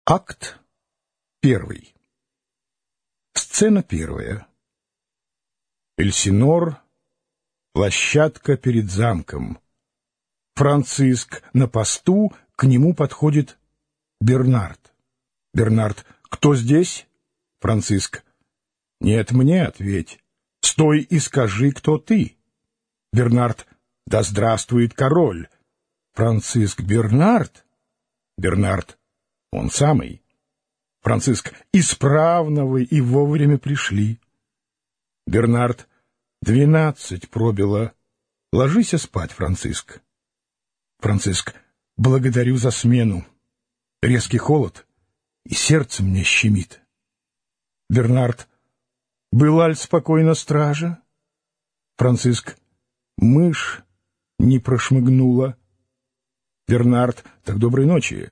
Аудиокнига Гамлет. Ричард III | Библиотека аудиокниг
Прослушать и бесплатно скачать фрагмент аудиокниги